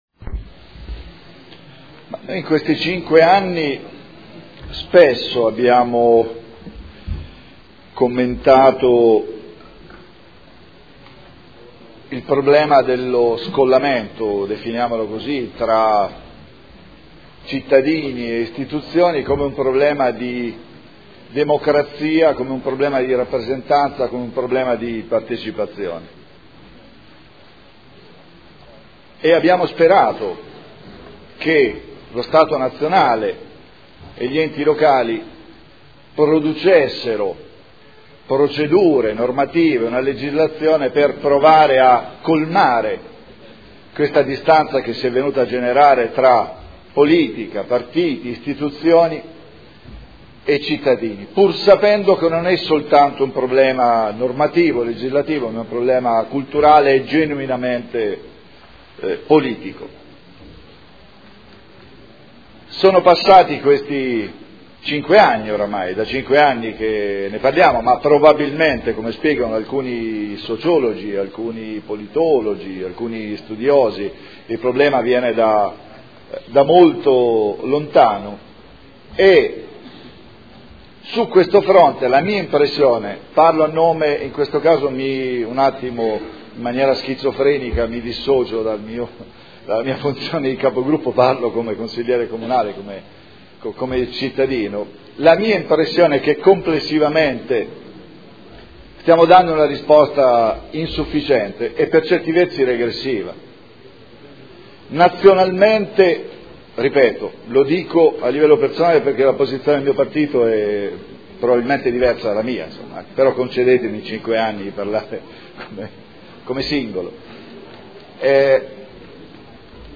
Proposta di deliberazione: Regolamento di prima attuazione della partecipazione territoriale – Approvazione. Dibattito